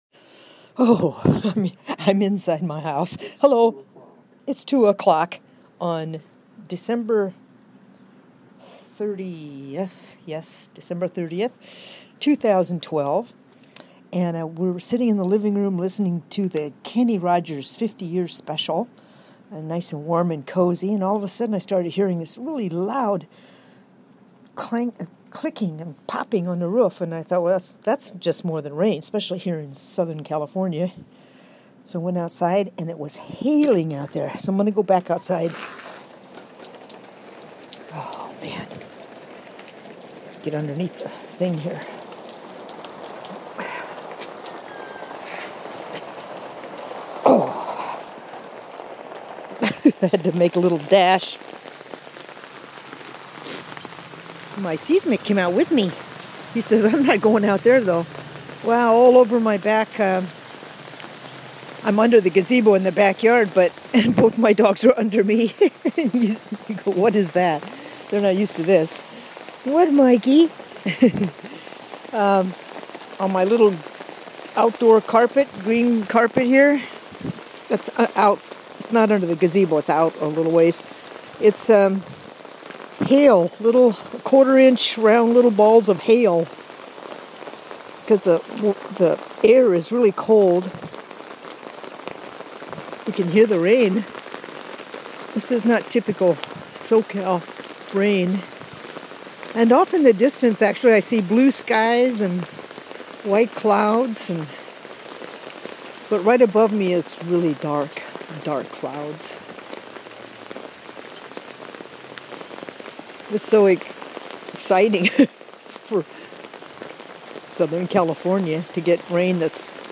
Hail in SoCal USA